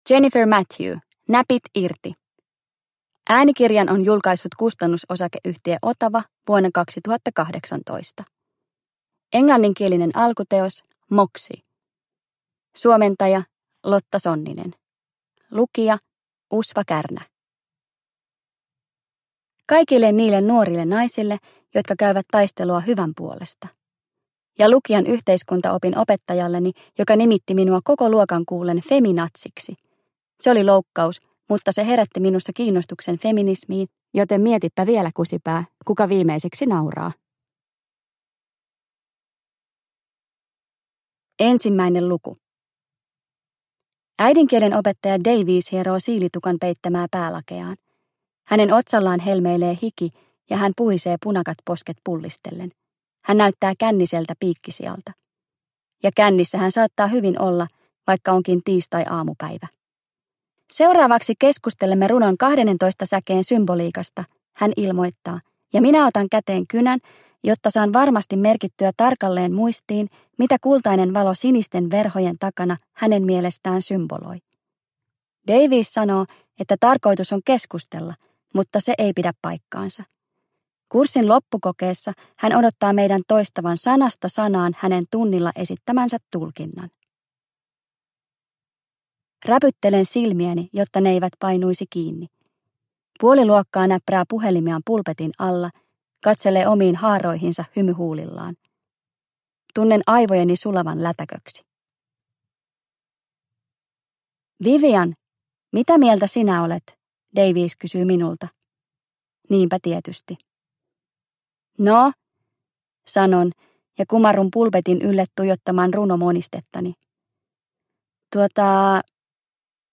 Näpit irti! – Ljudbok – Laddas ner